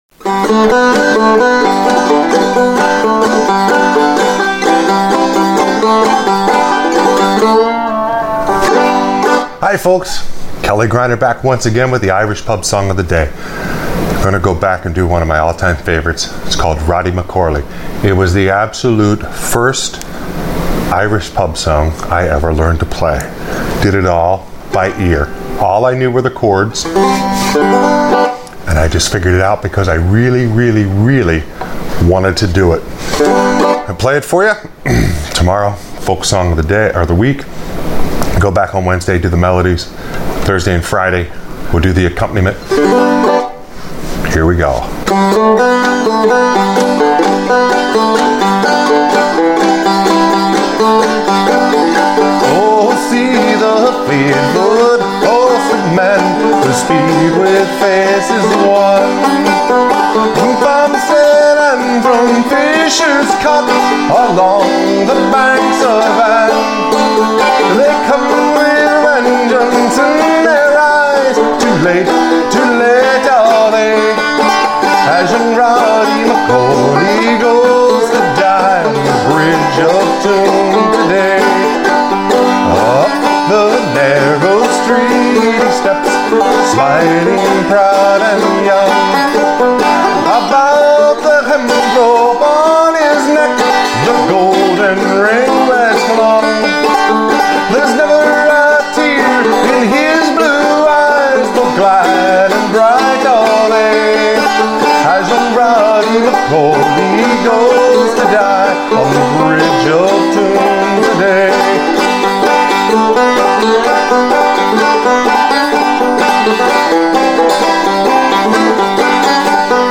Even with two “extra” chords the melody is still on the first two frets.